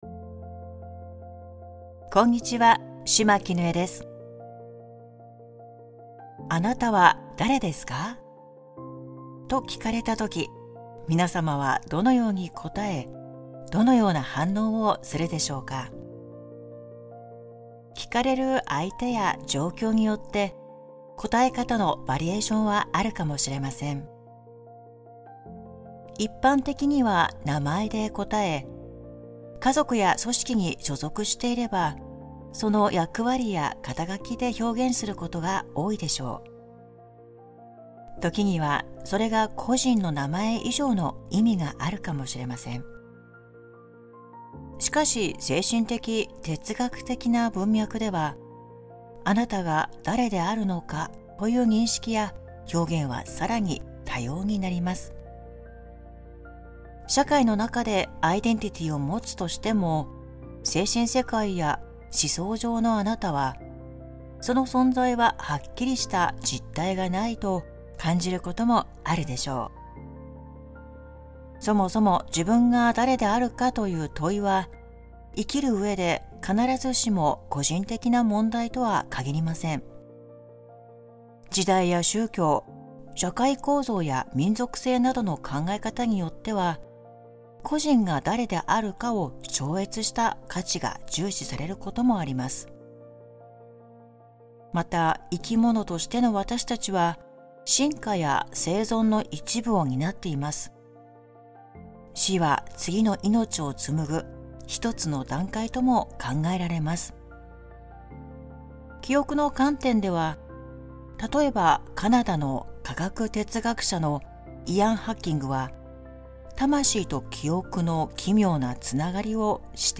※ [ 7:48 頃 ] 〈「あなたが誰であるべきか」を手放す〉誘導ワーク♪